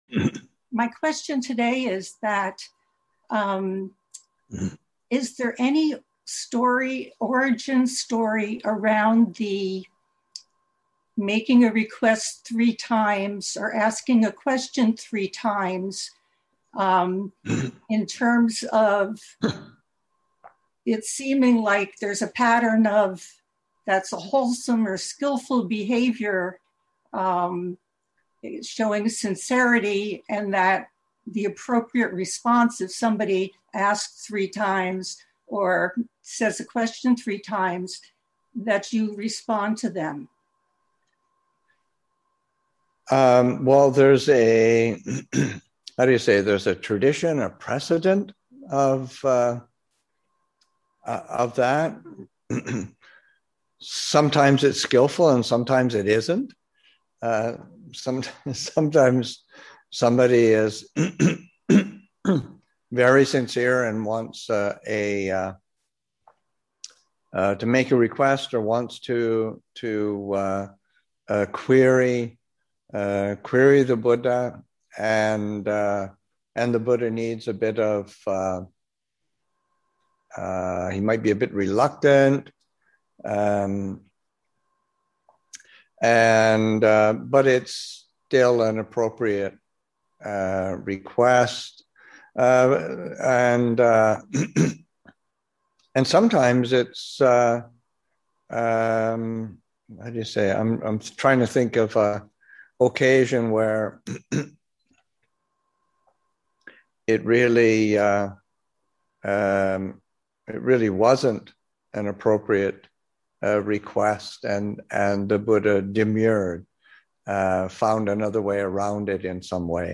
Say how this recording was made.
Online from Abhayagiri Buddhist Monastery in Redwood Valley, California